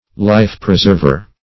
Meaning of life-preserver. life-preserver synonyms, pronunciation, spelling and more from Free Dictionary.
Life-preserver \Life"-pre*serv`er\ (l[imac]f"pr[-e]*z[~e]rv`[~e]r), n.